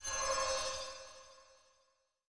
Uncanny Sound
神秘的声音